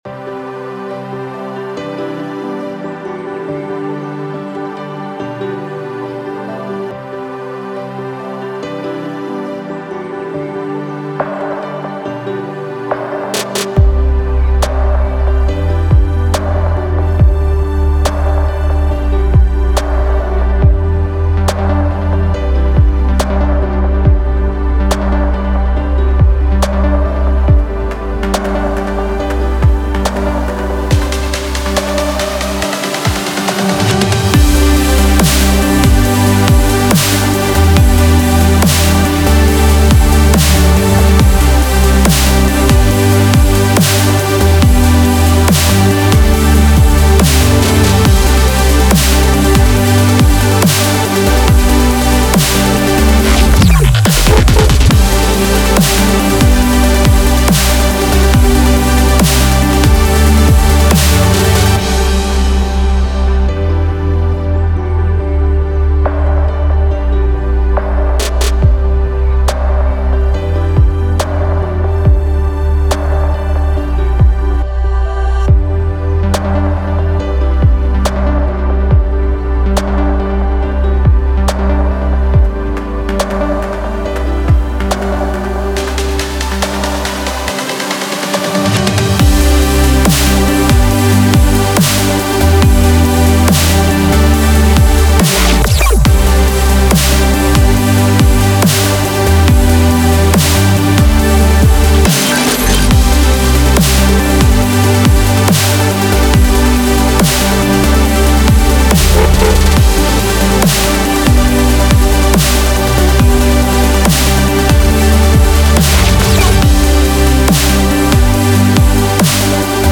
Drum, Heavy Bass, Piano, Strings, Synth